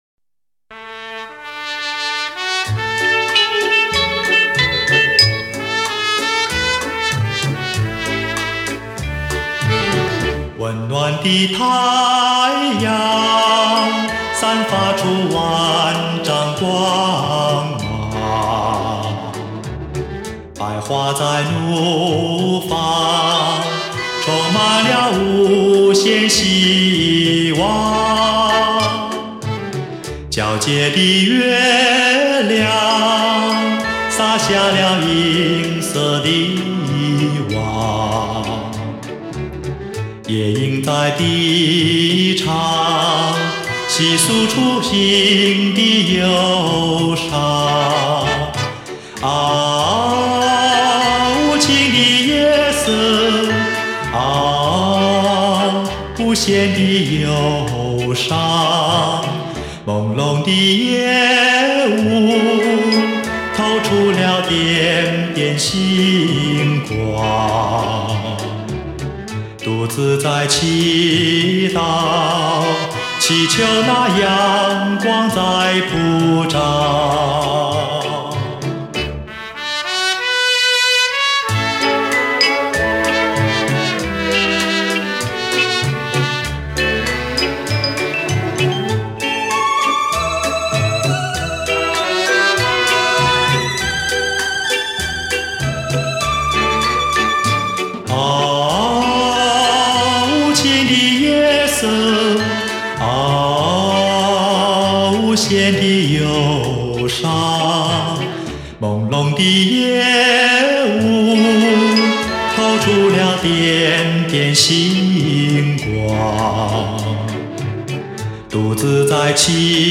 复刻原装黑胶唱片双封套，原汁原味…